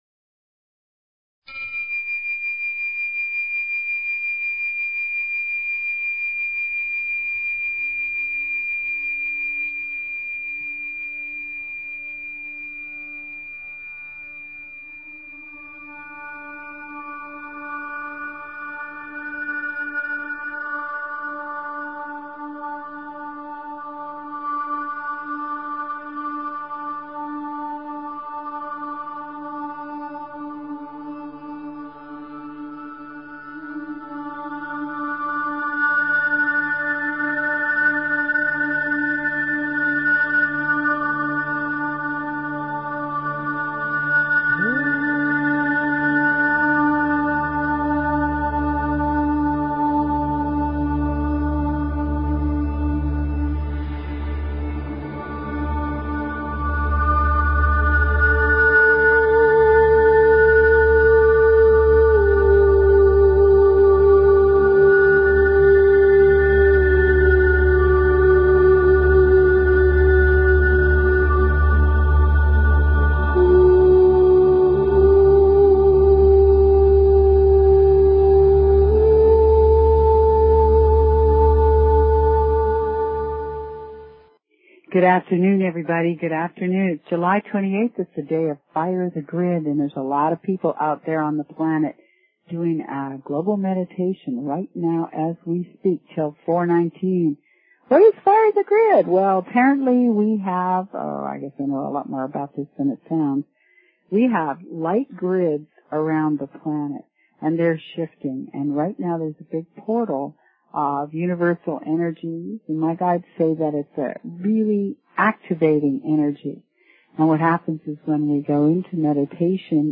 Talk Show Episode, Audio Podcast, Radiance_by_Design and Courtesy of BBS Radio on , show guests , about , categorized as
Radiance by Design is a call in show about you, about subconscious patterns of your soulular/cellular lineage that keep you rooted in life and how to release yourself from their grip.